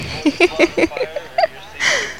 Play, download and share Silly laugh.1 original sound button!!!!
silly-laugh_dDsYsH9.mp3